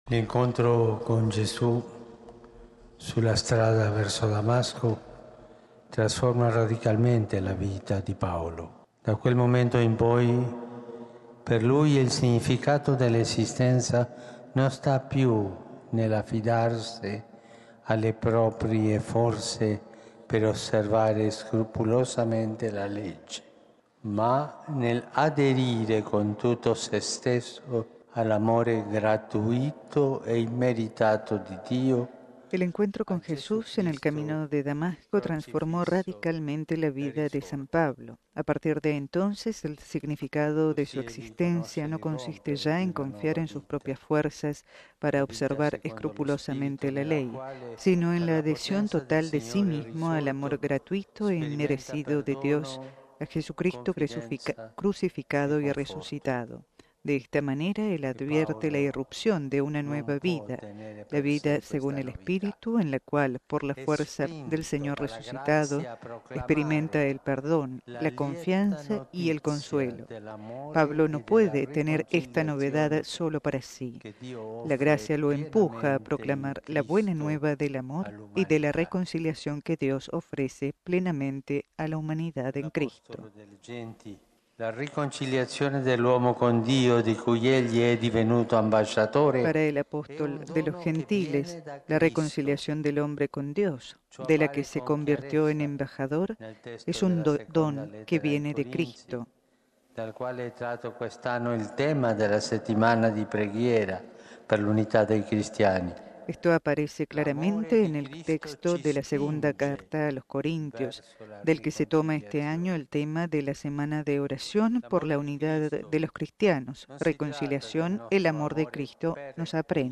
(RV).- Como es tradición, el Santo Padre Francisco se trasladó la tarde del miércoles 25 de enero a la Basílica de San Pablo Extramuros en Roma, para presidir la celebración de las segundas Vísperas en la Solemnidad de la Conversión del Apóstol de Pablo. En el marco de la conclusión de la Semana de Oración para la Unidad de los cristianos, estuvieron presentes en la celebración los representantes de otras Iglesias y Comunidades eclesiales.